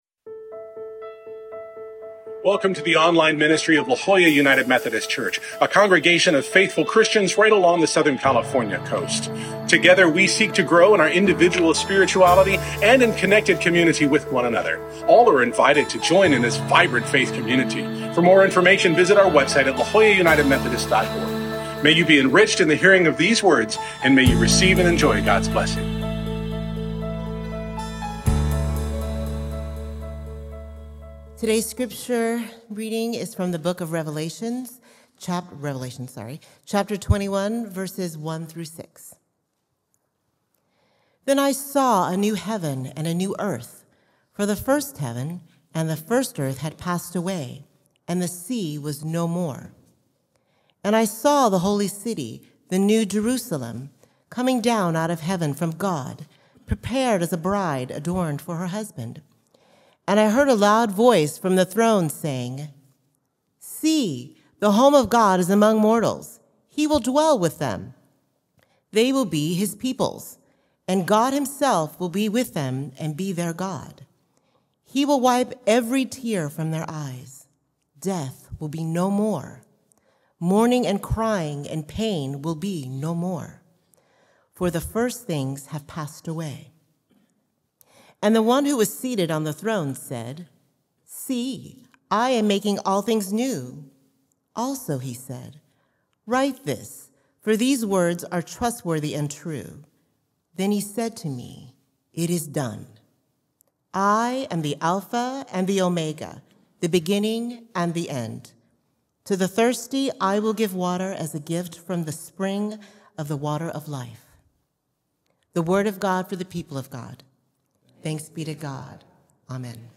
The Sermon and Scripture for May 18th